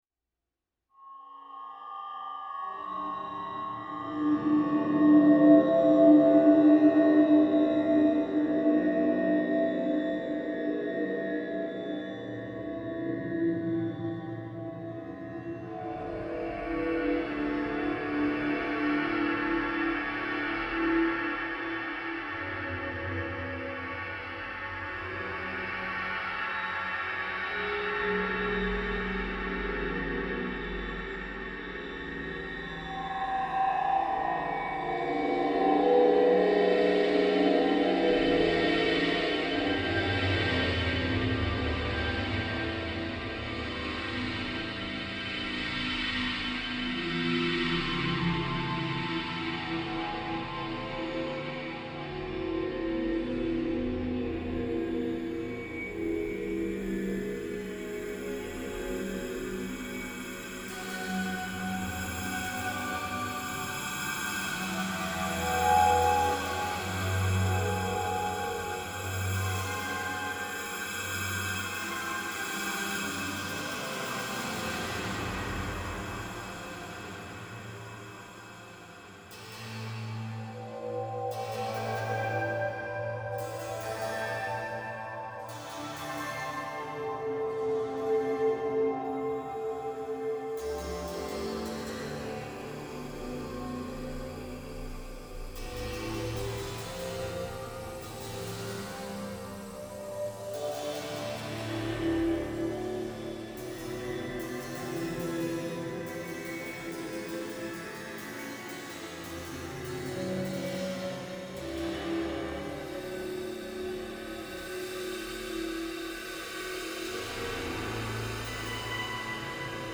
During the "viewing" and "cookie/cider-consuming" periods, I thought it might be fun to have some 'ambient' music happening.
I had planned to put both of the "rehearse" pieces on-line shortly after we did them, but my laptop suffered a massive logic board failure and had to be sent back to Apple for repair.
guitars and guitar processing